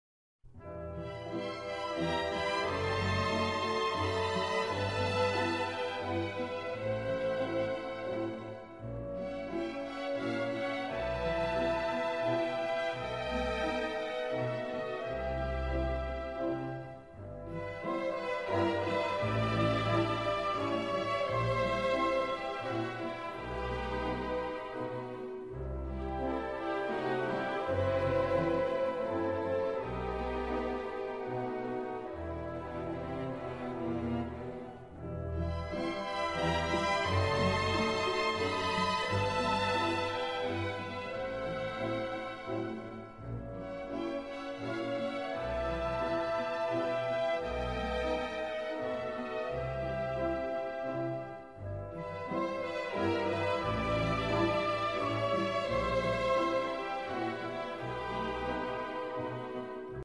Orchestral Works